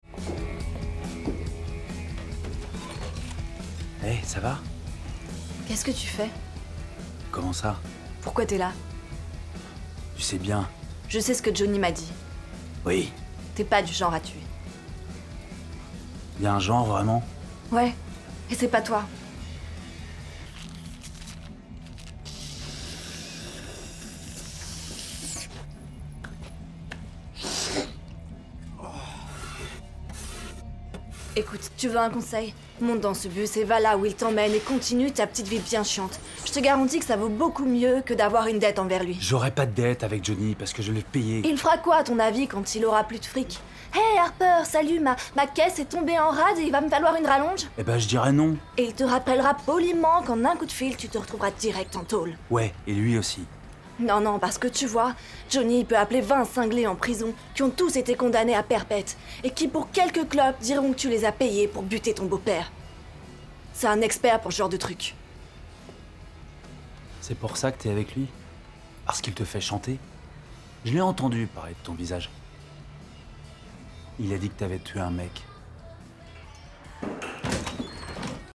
DOUBLAGE SERVEUSE AUTOROUTE